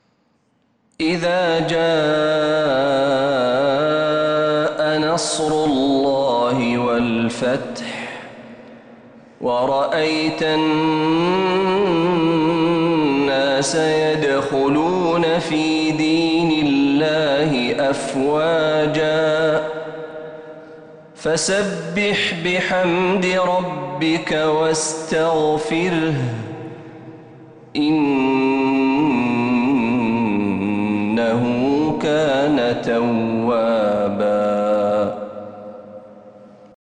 سورة النصر كاملة من مغربيات الحرم النبوي للشيخ محمد برهجي | ربيع الآخر 1446هـ > السور المكتملة للشيخ محمد برهجي من الحرم النبوي 🕌 > السور المكتملة 🕌 > المزيد - تلاوات الحرمين